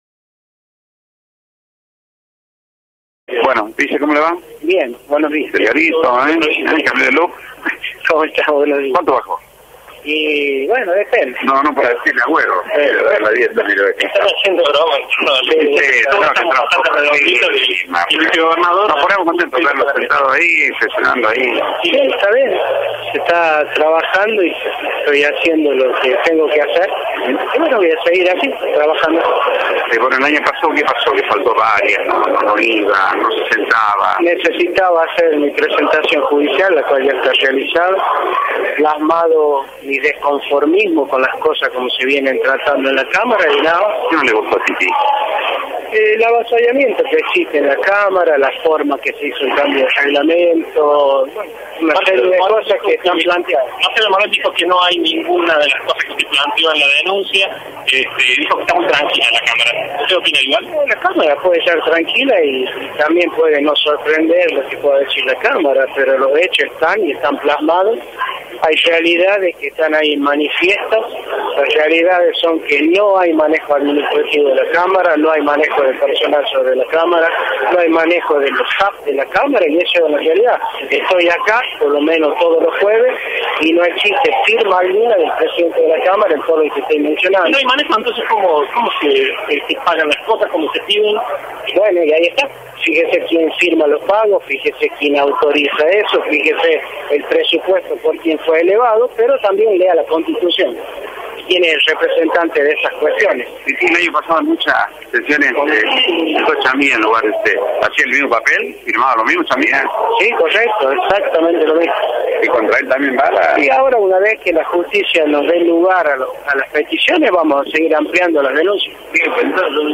«Voy a decir a quién se le entregó la plata», adelantó Bosetti en declaraciones a Radio La Voz (89.7 FM) en relación a la causa de las ONG´s que está virtualmente parada en la Justicia Federal.